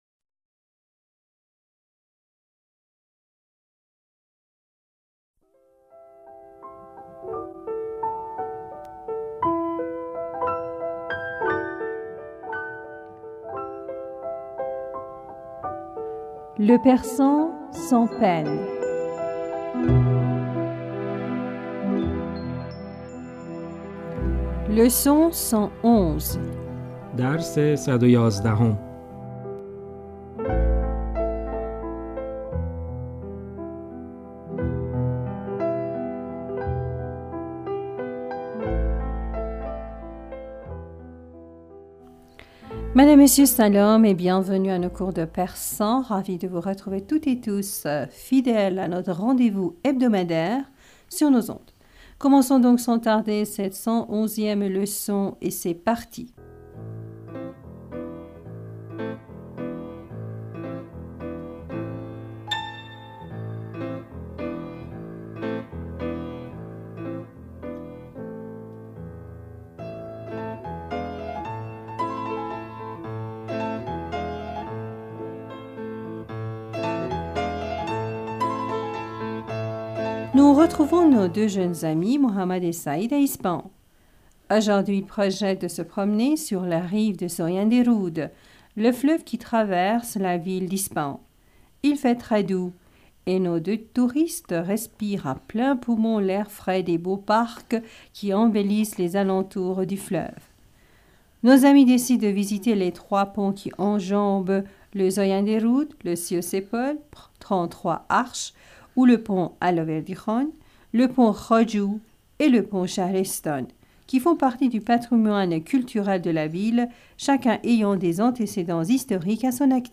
Ecoutez et répétez après nous.